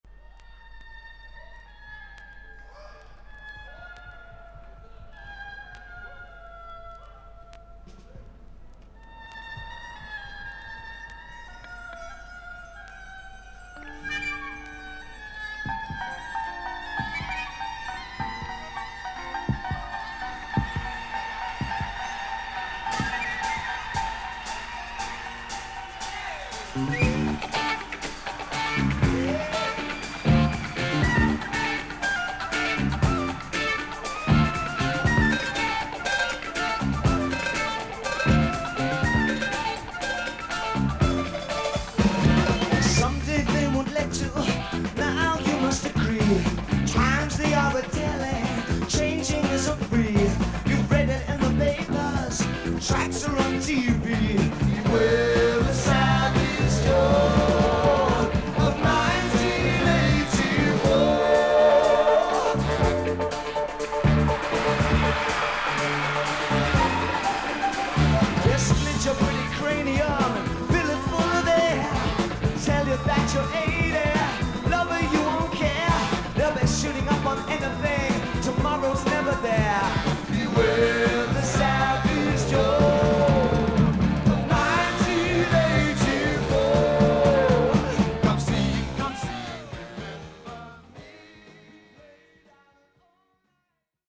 少々軽いパチノイズの箇所あり。少々サーフィス・ノイズあり。クリアな音です。